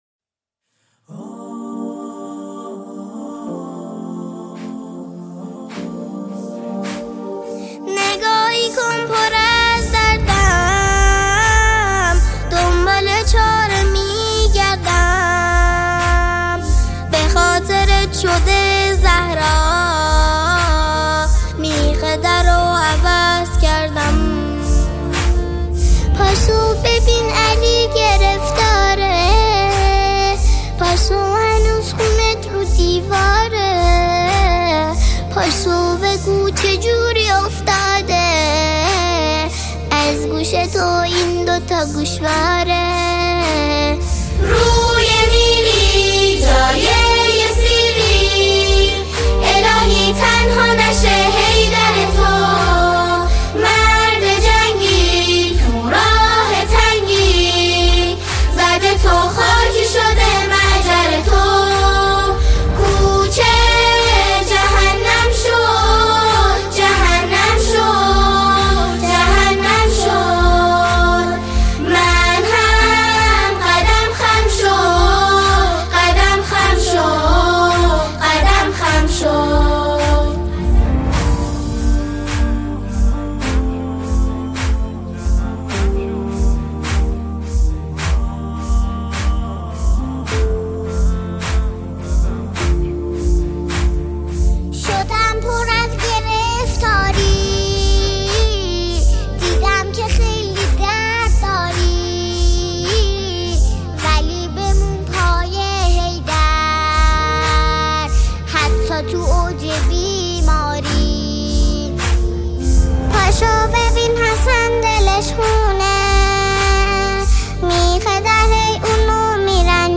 سرودهای حضرت فاطمه زهرا سلام الله علیها